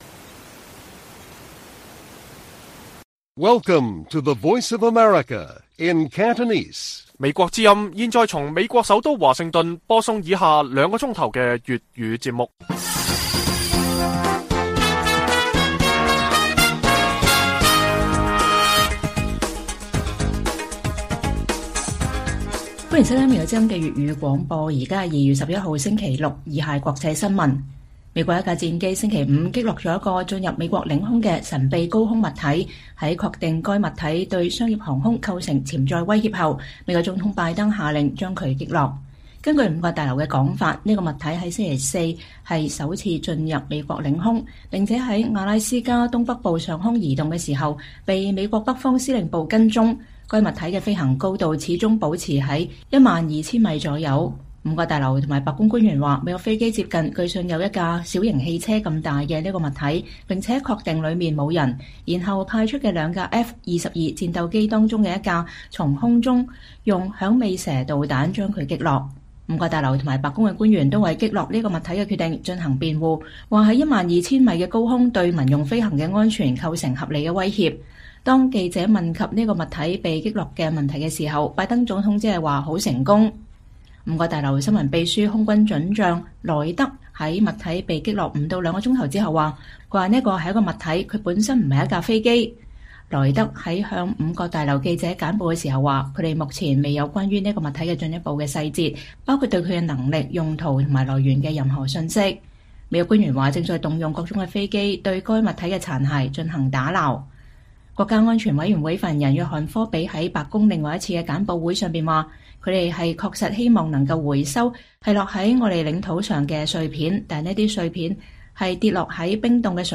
粵語新聞 晚上9-10點：美國在阿拉斯加上空擊落神秘高空物體